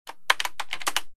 keyboard3.ogg